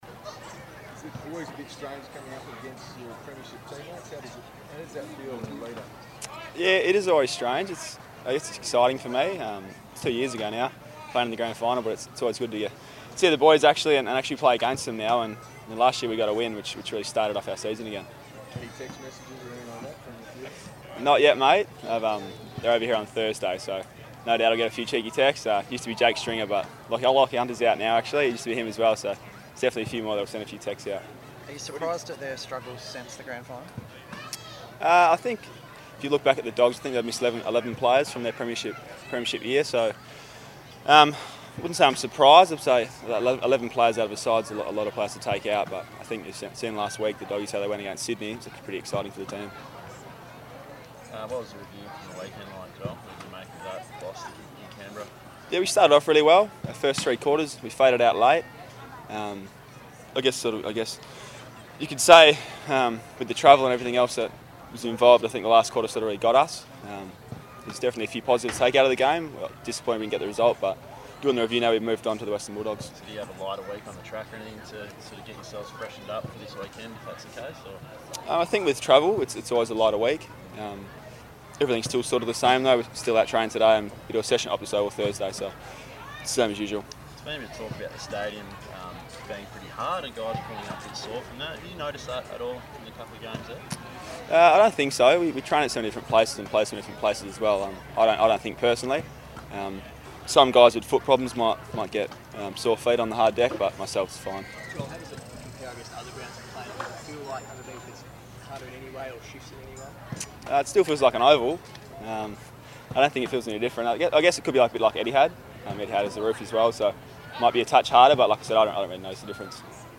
Joel Hamling media conference - 17 April